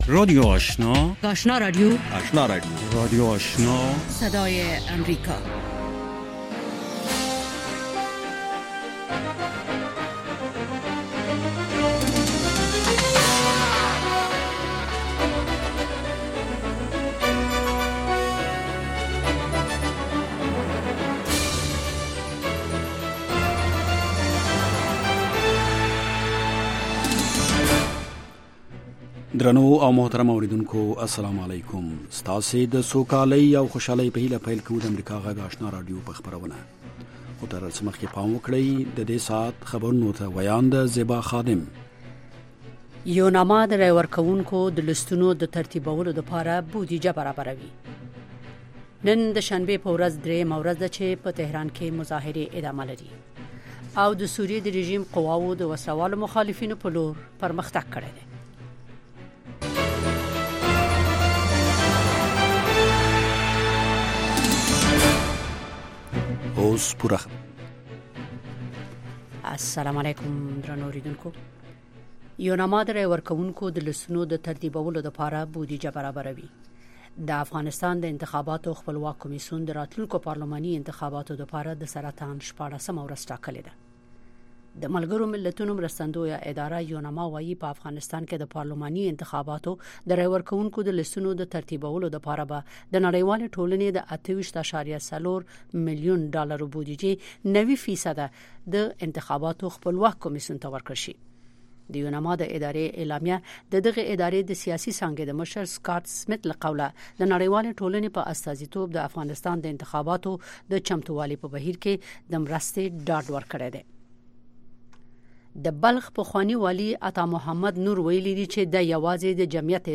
لومړنۍ ماښامنۍ خبري خپرونه